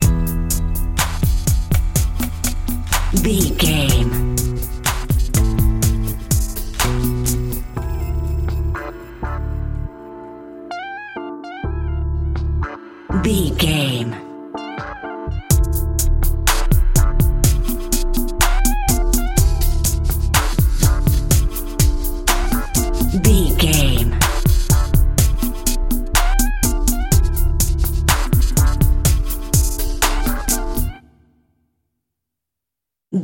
Aeolian/Minor
synthesiser
drum machine
hip hop
soul
Funk
neo soul
acid jazz
r&b
energetic
bouncy
funky